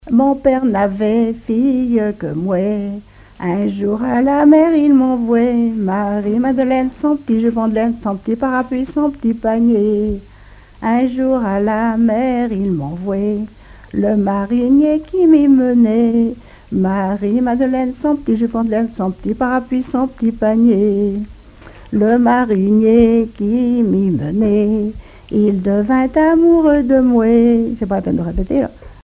Genre laisse
collecte en Vendée
Pièce musicale inédite